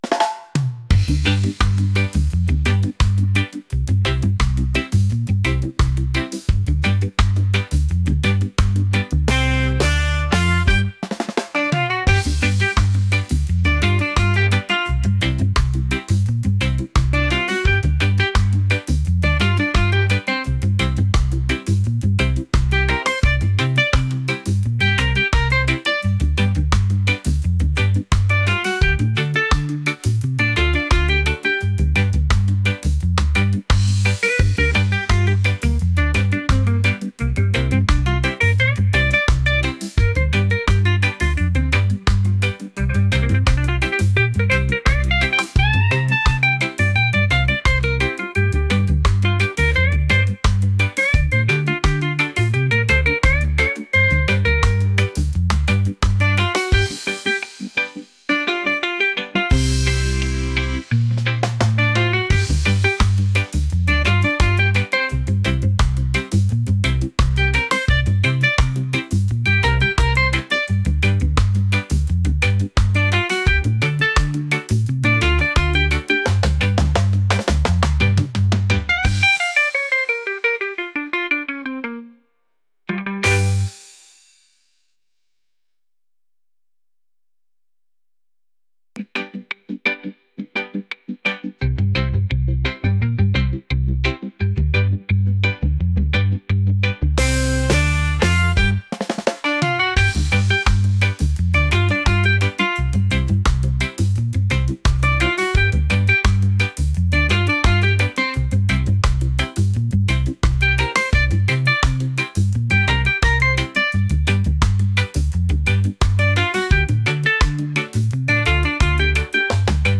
reggae | groovy | upbeat